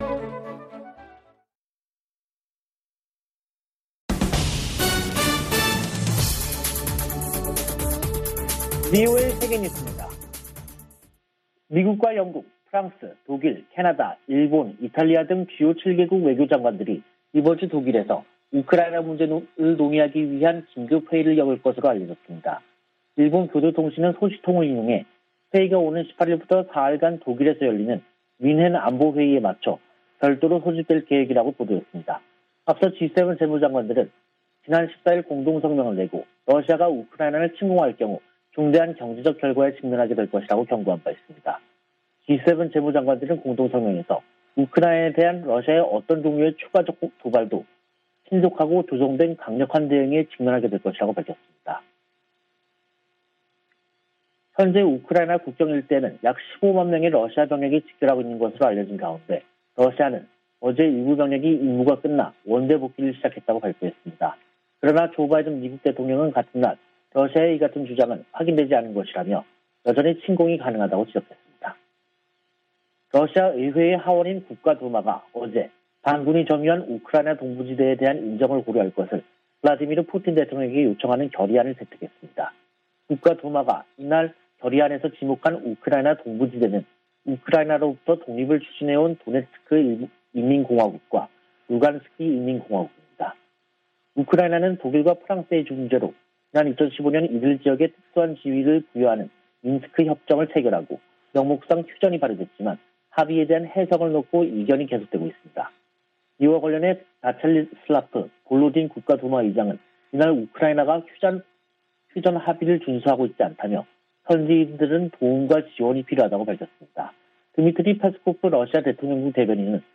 VOA 한국어 간판 뉴스 프로그램 '뉴스 투데이', 2022년 2월 16일 2부 방송입니다. 웬디 셔먼 미 국무부 부장관이 일본 외무성 사무차관과 전화 협의를 갖고 북한에 진지한 외교 복귀를 촉구했습니다.